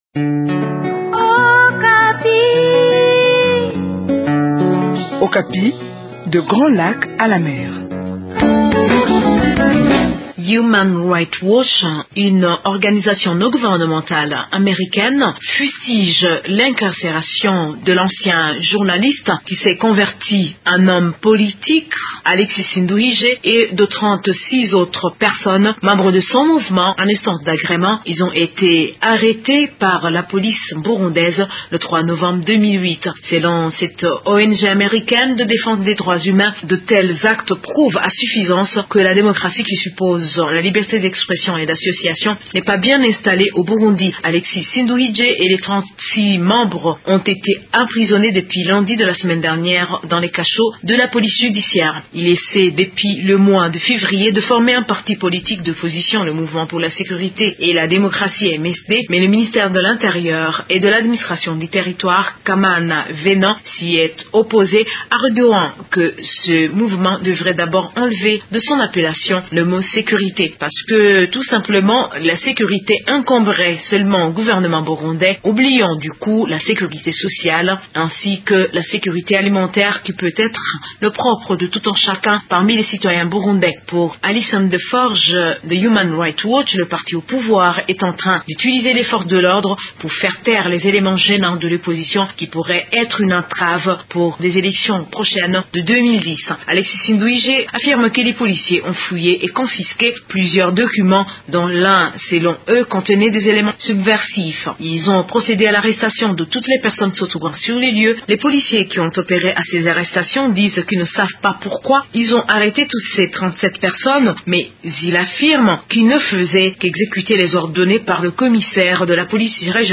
De Bujumbura, une correspondance